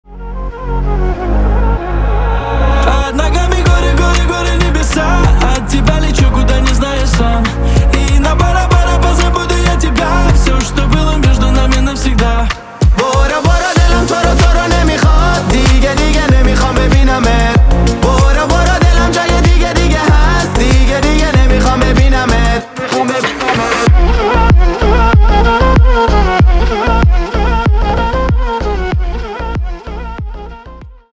поп
восточные